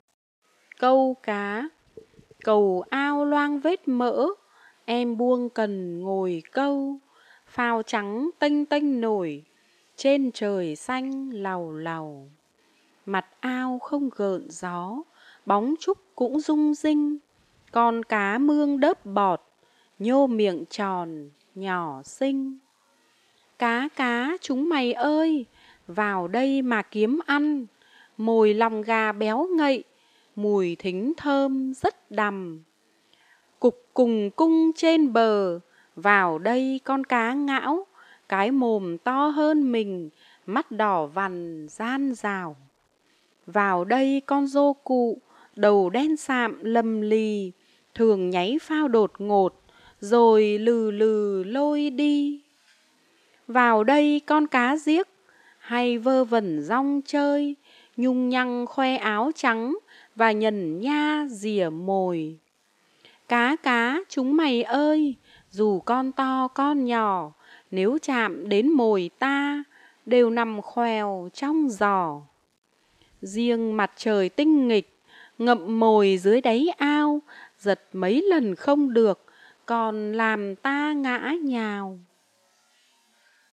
Sách nói | Bài thơ "Câu cá"